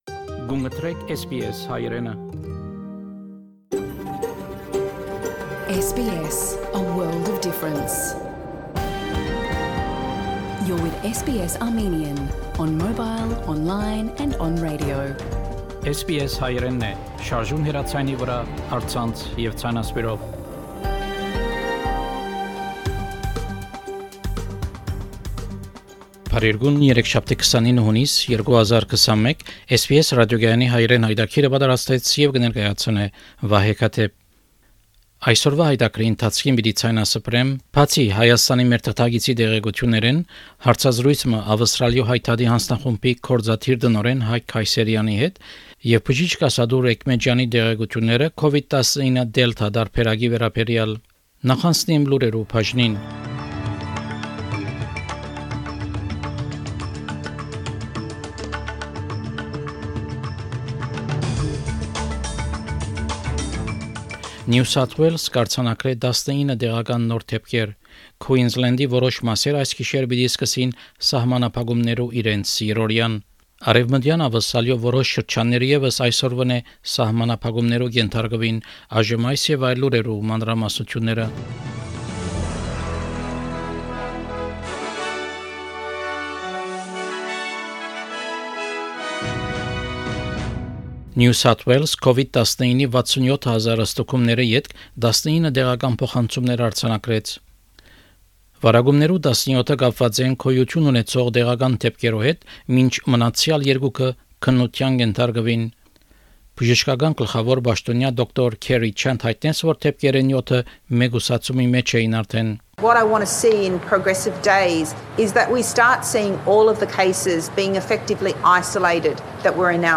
SBS Armenian news bulletin from 29 June 2021 program.